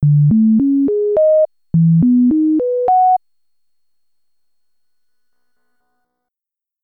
Here's some Echo slider examples to illustrate the difference in sound:
EXAMPLE 3: Example 1 but mixed down to mono (i.e. no delay is heard):
c700_echo_mixdown2mono.mp3